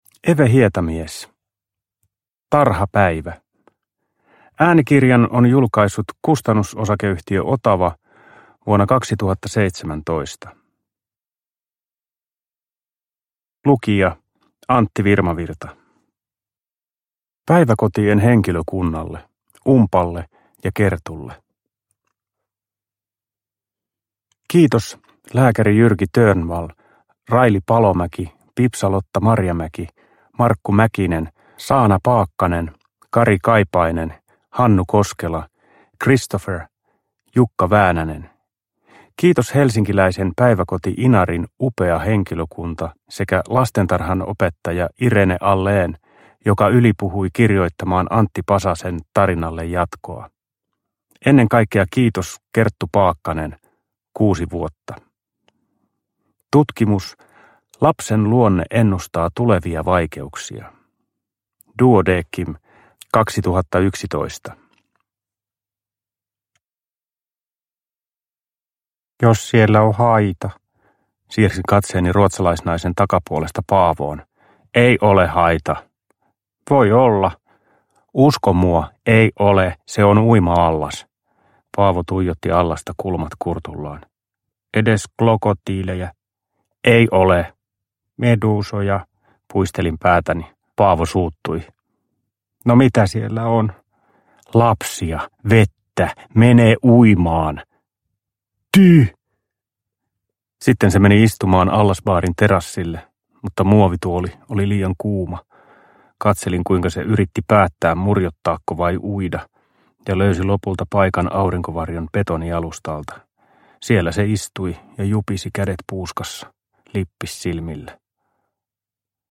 Tarhapäivä – Ljudbok – Laddas ner